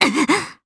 Valance-Vox_Damage_jp_06.wav